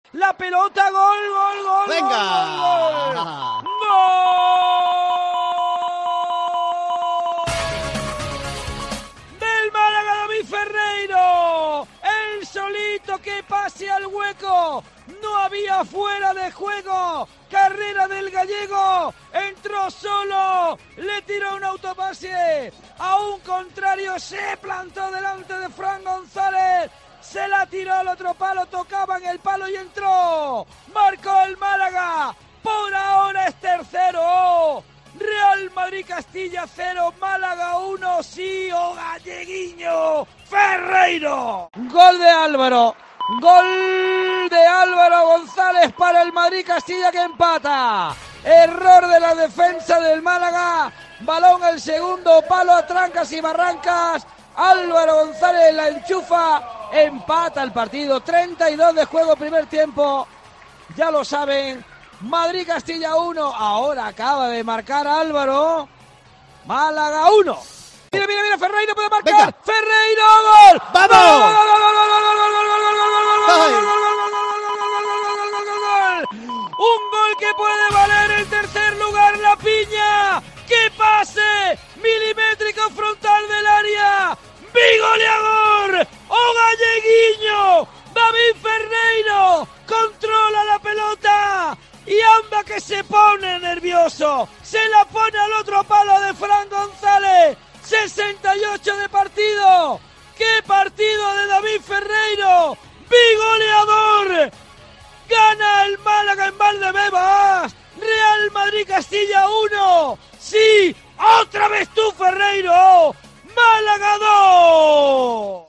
Así te hemos narrado la victoria del Málaga CF ante el Real Madrid Castilla (1-2)